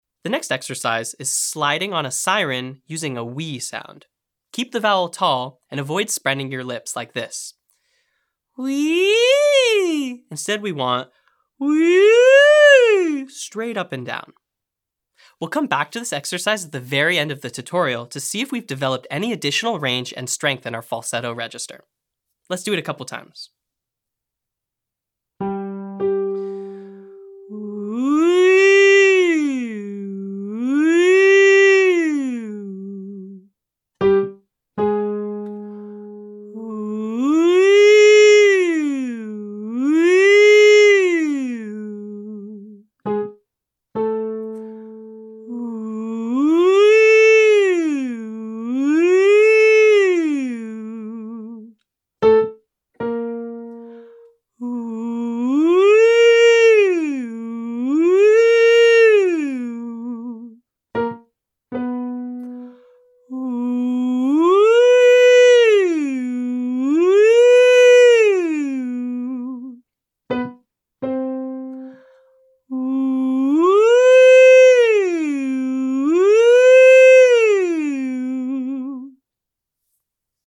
• Owl Hoot/woohoo cheer imitation to access your head voice.
• SOVT Lip Trill or straw phonation (1-8-1) to warm up head voice using a partially occluded space.
• Siren slide on "Wee" to stretch and develop falsetto range.